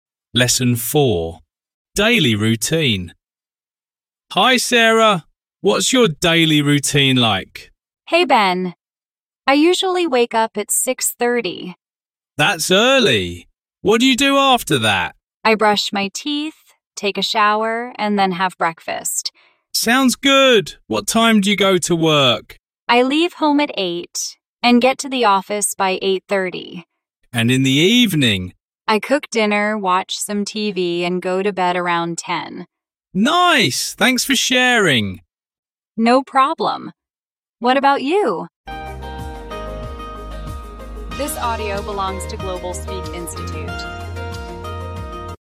Giọng chậm